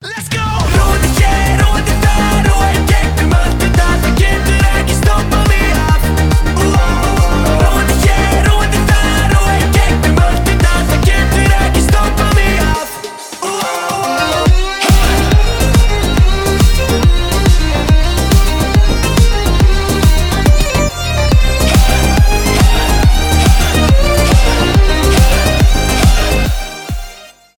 быстрые
энергичные , танцевальные
folktronica
веселые